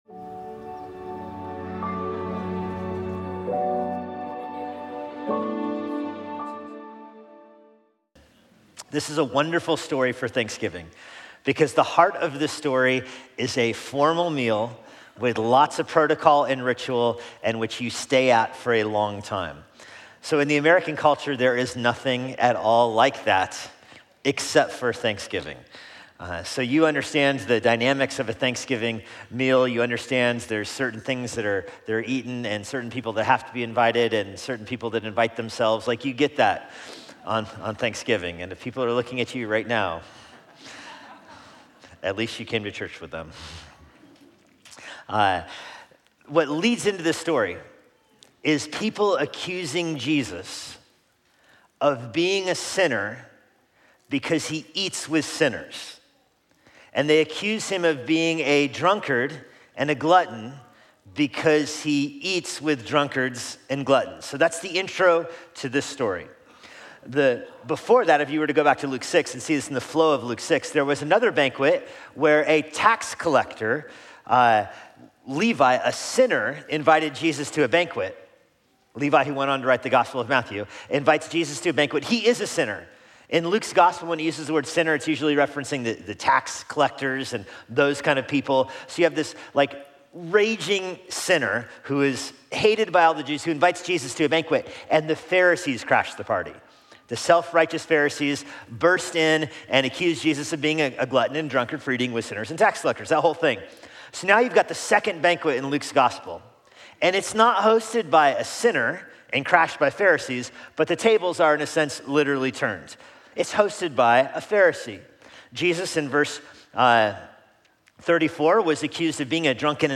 Thanksgiving Day Sermon